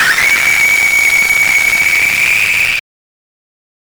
Doll Sound Effects - Free AI Generator & Downloads
a horror creepy doll giving instant horrific jumpscare
a-horror-creepy-doll-givi-6y5fsgil.wav